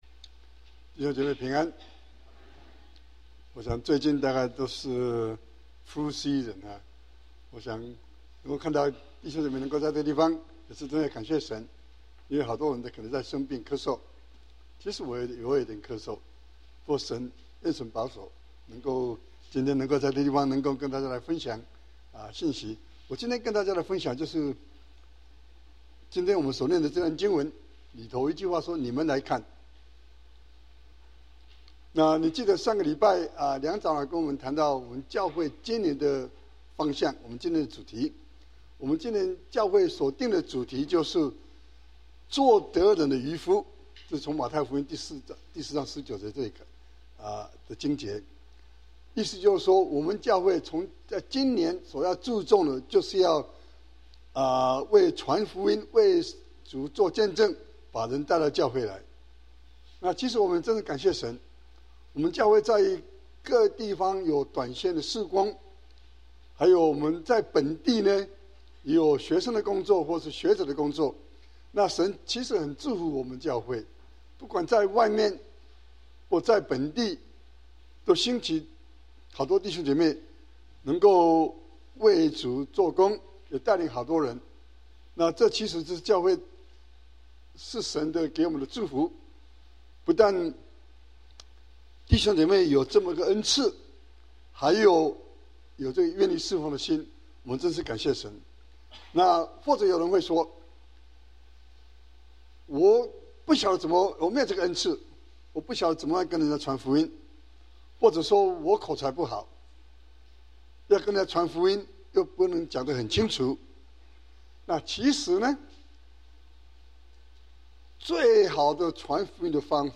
中文講道 | 基督教華府中國教會